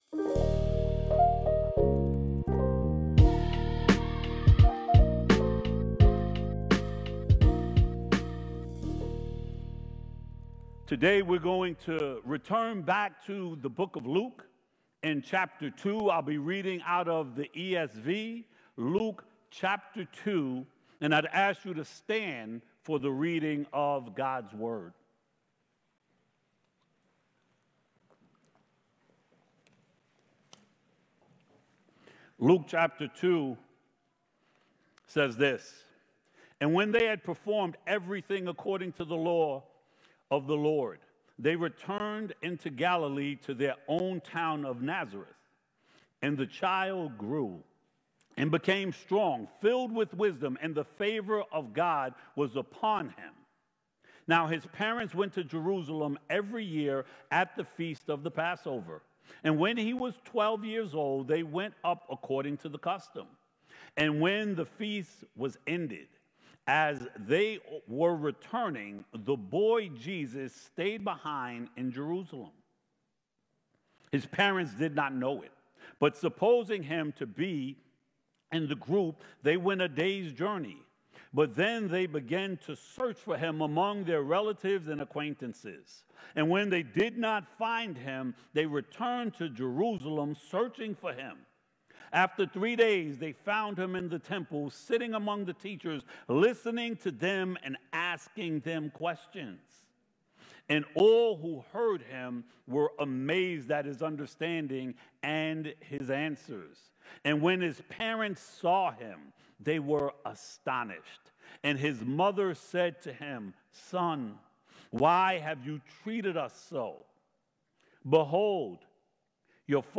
Sermons from Light of the World Church in Minisink Hills, PA